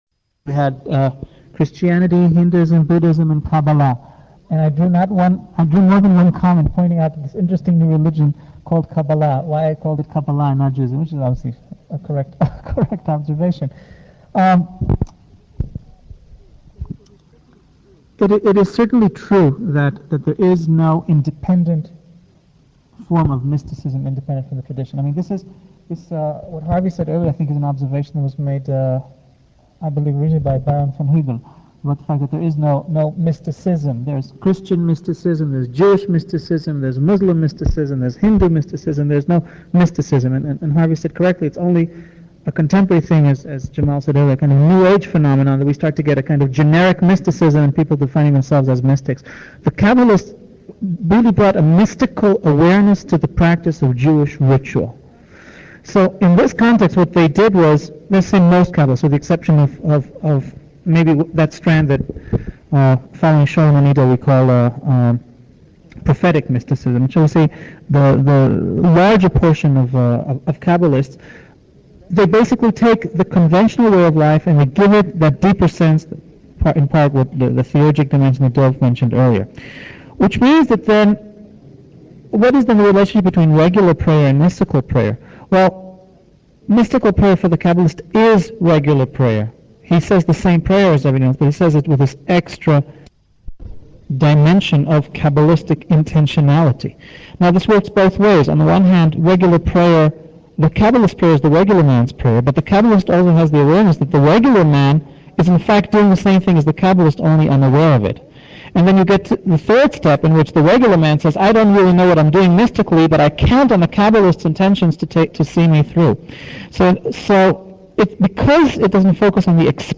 Audio Lectures on topics like interfaith alliance,interfaith christian,interfaith community,interfaith council,interfaith jewish